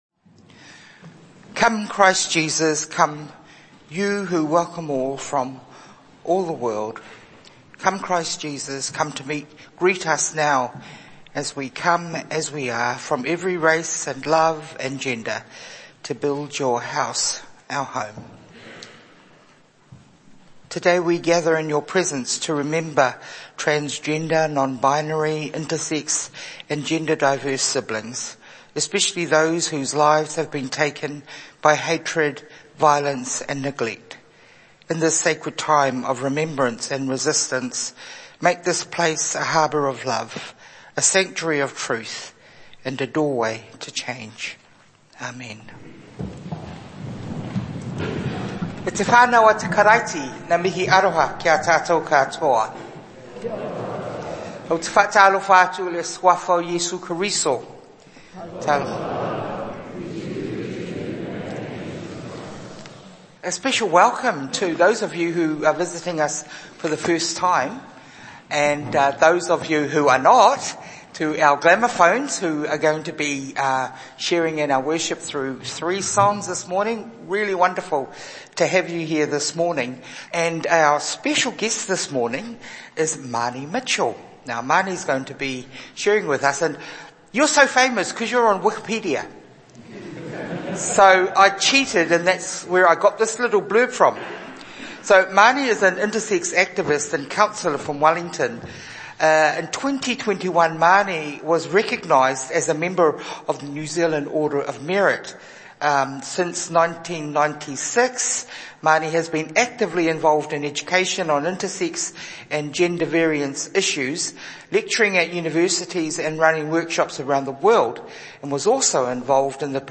Audio from the service at St Andrew's on the Terrace, Wellington to commemorate Transgender Day of Remembrance.
This recording has been edited, with some parts of the service not included.
The service, marking Transgender Day of Remembrance on 23 November 2025, weaves together worship, personal testimony, music and political context to honour transgender, non-binary, intersex and gender diverse communities, and to remember those lost to violence, hatred, neglect and suicide.